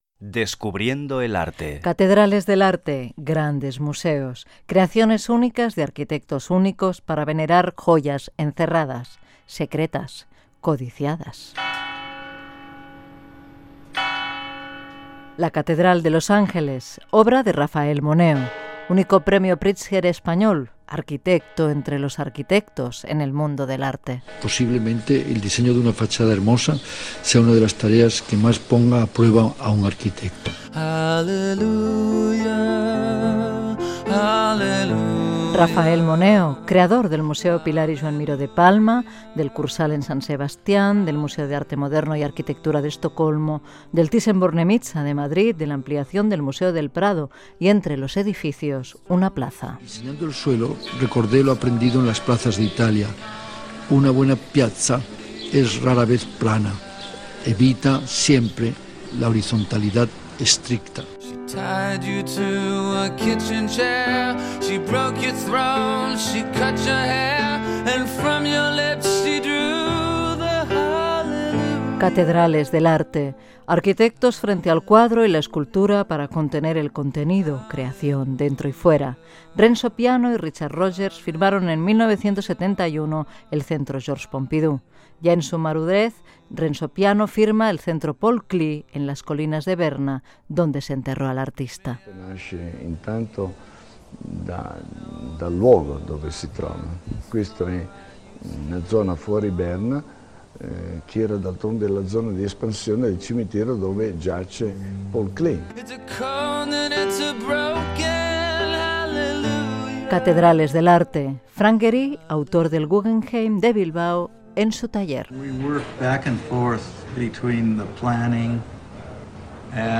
"Descubriendo el arte" dedicat a les "Catedrals de l'art": amb declaracions dels arquitectes que han construït grans edificis de diferents museus d'art
Cultura